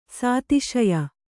♪ sātiśaya